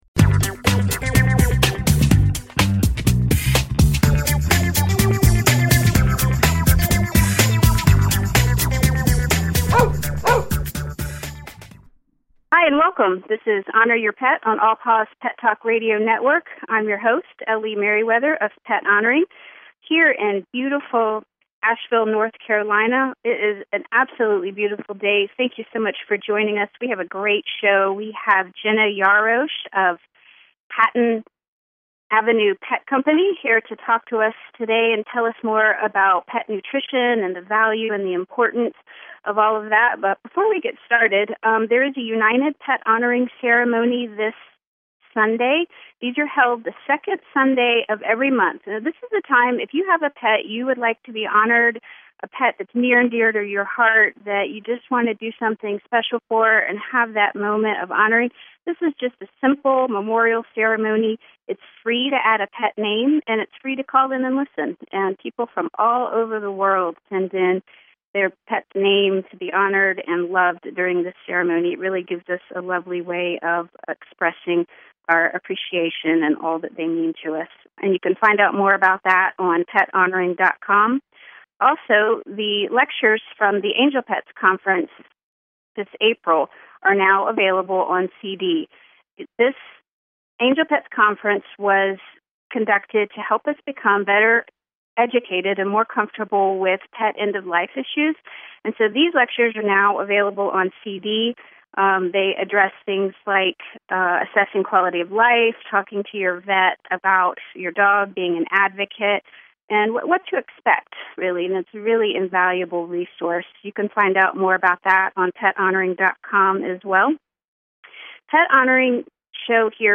Honor Your Pet is a talk radio show covering all things which honor our pets and our relationship with them. These include innovative and holistic pet care topics as well as addressing the difficult, but honorable end-of-life times and healing grief from pet loss.